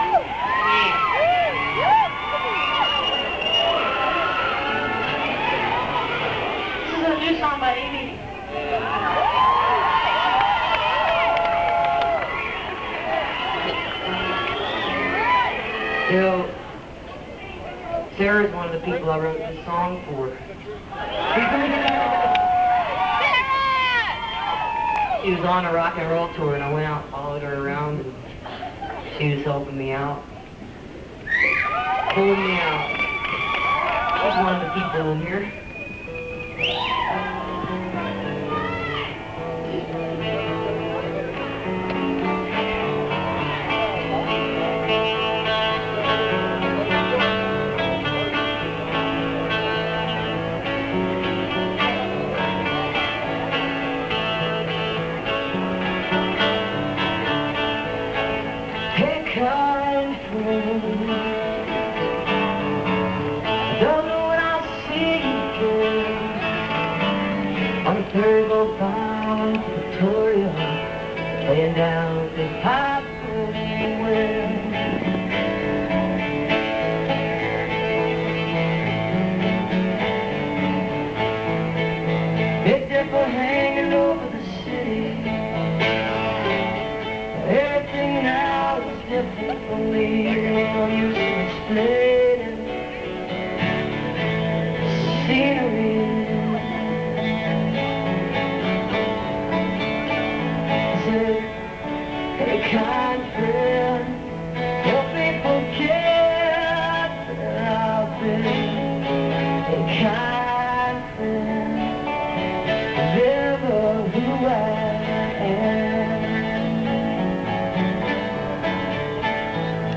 (incomplete, band show)